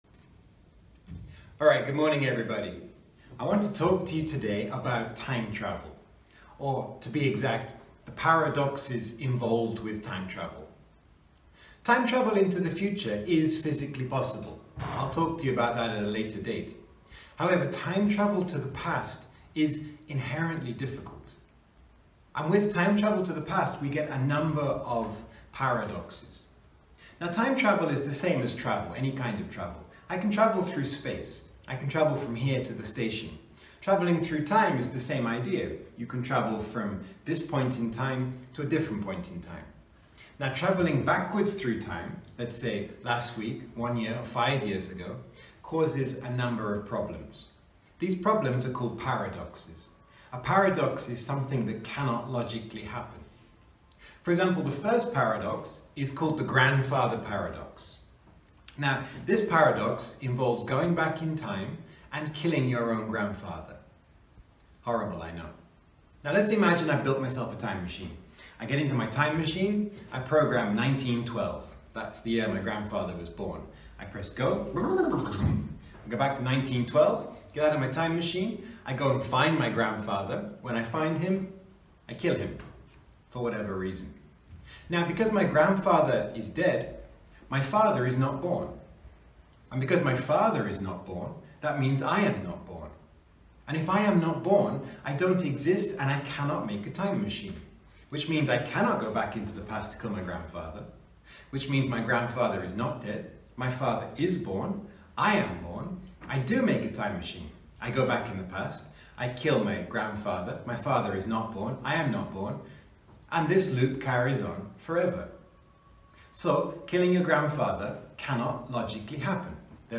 Posted in Long talk | Tagged , , | Comments Off on #2 – Time Travel Paradoxes